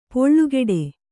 ♪ poḷḷugeḍe